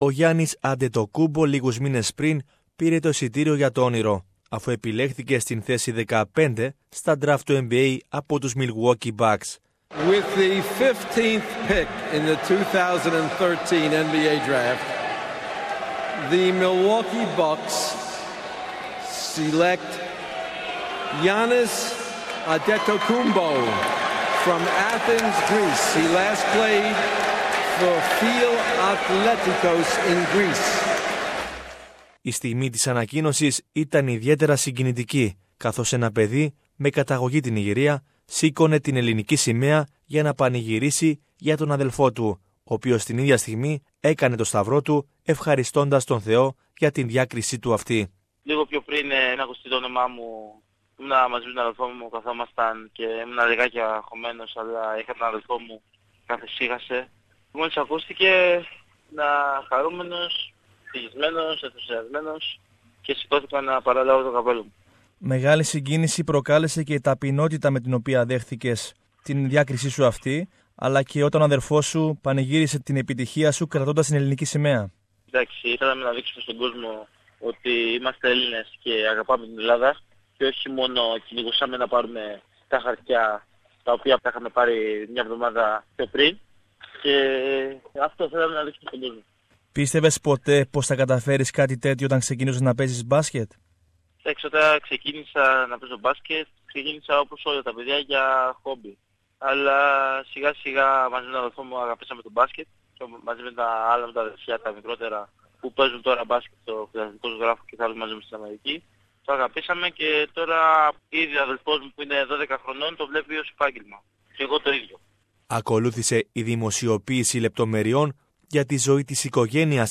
O Γιάννης Αντετοκούνμπο μιλά αποκλειστικά στο SBS